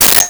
Sword Hit 04
Sword Hit 04.wav